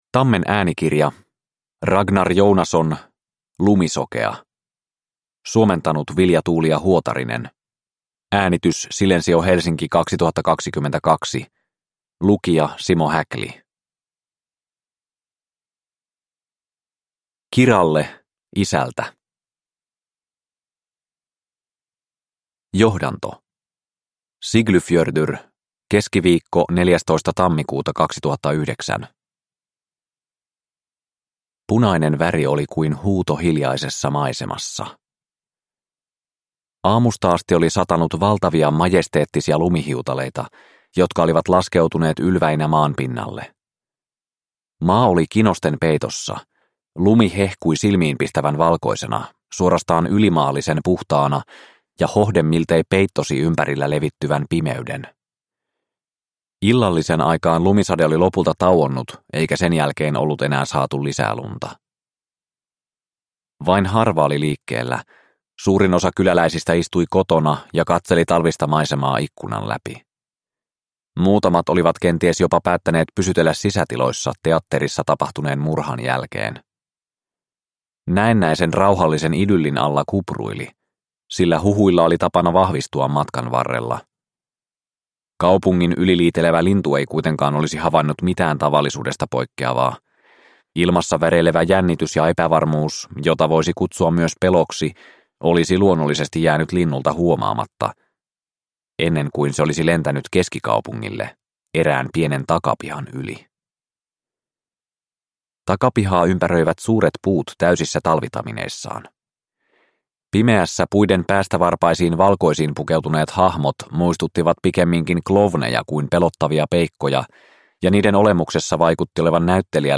Lumisokea – Ljudbok – Laddas ner